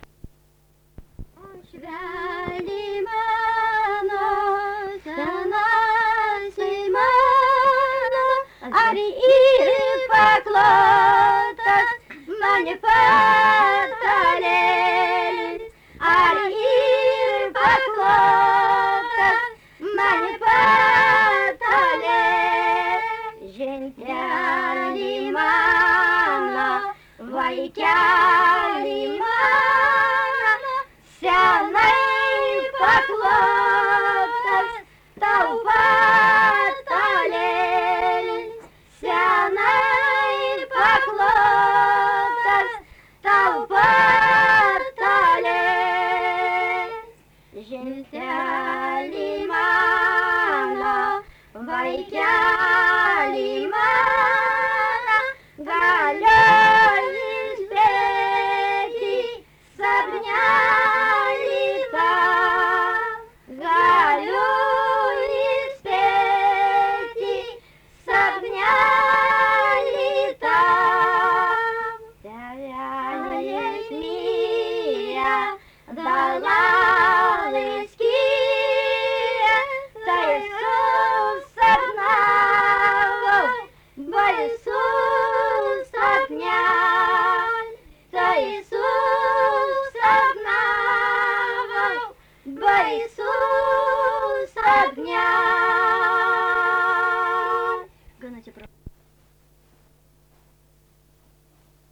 daina
Antanašė
vokalinis